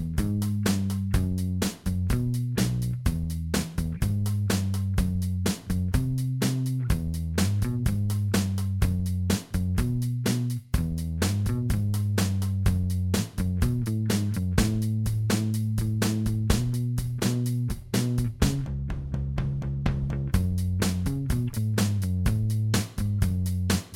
Minus All Guitars Pop (1960s) 2:10 Buy £1.50